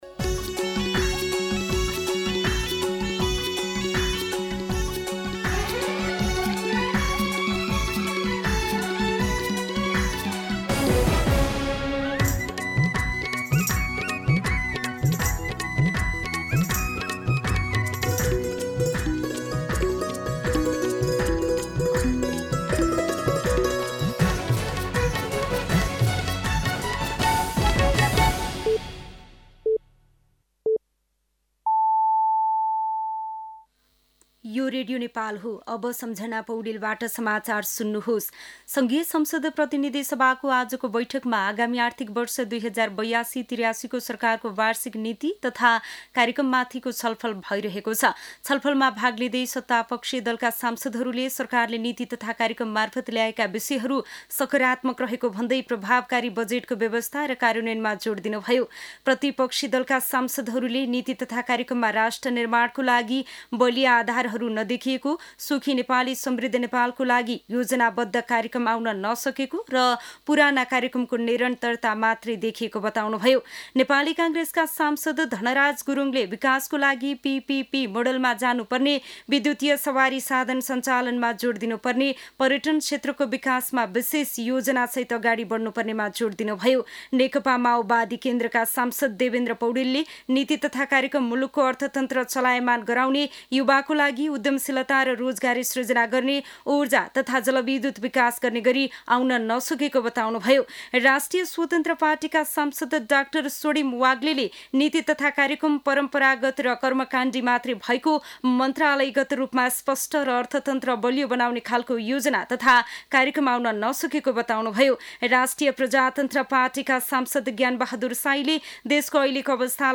दिउँसो ४ बजेको नेपाली समाचार : २३ वैशाख , २०८२